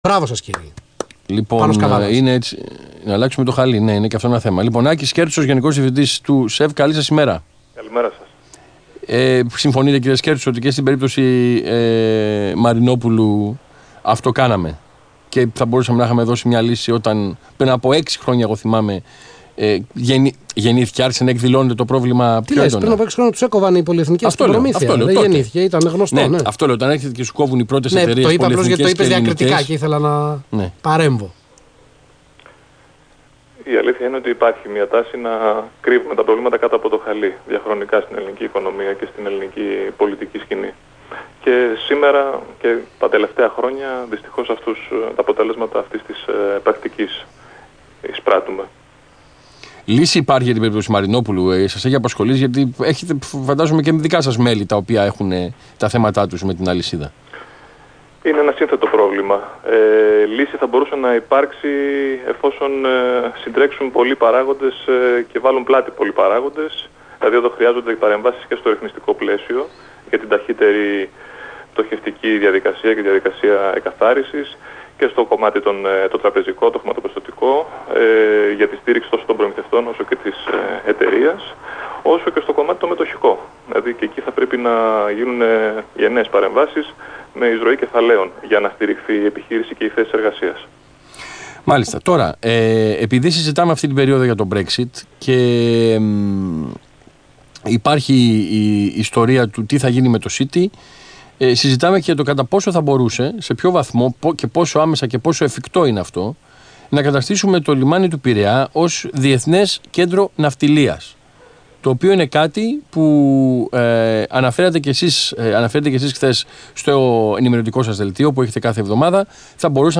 Ο κ. Άκης Σκέρτσος, Γενικός Διευθυντής του ΣΕΒ στον Ρ/Σ Αθήνα 9.84, 1/7/2016